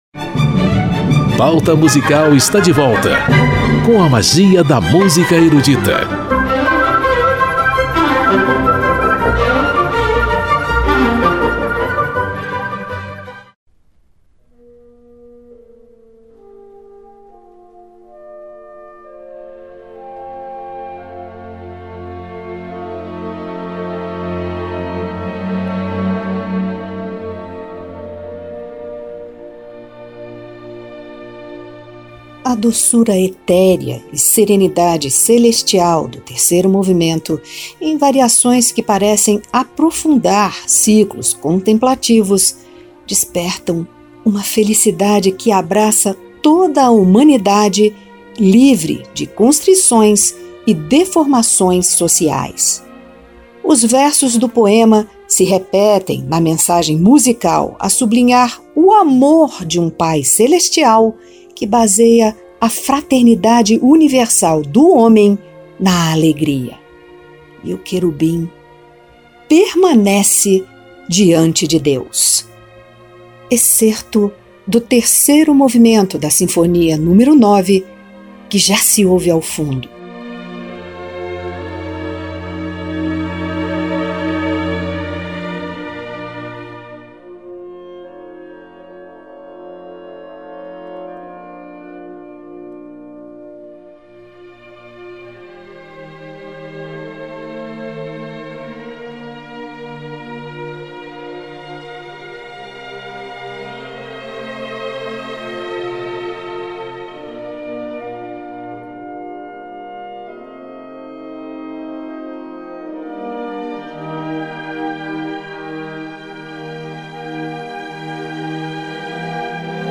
Solistas de renome, Wiener Singverein (Coro da Sociedade de Amigos da Música de Viena) e Filarmônica de Berlim, regidos por Herbert von Karajan, na interpretação da monumental Sinfonia "Coral" n. 9 em Ré Menor Op. 125, de L.v. Beethoven.